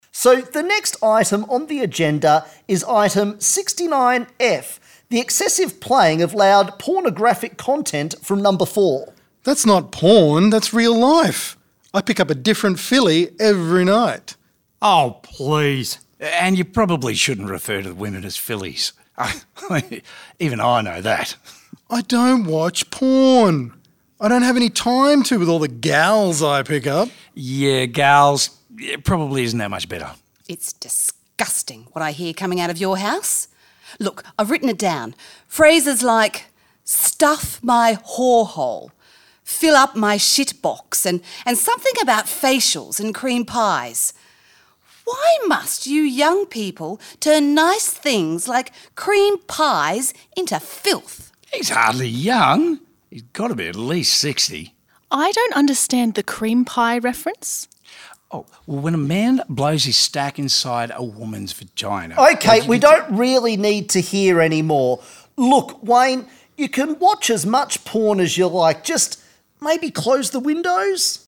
The-Body-Corporate-Meeting-Trailer.mp3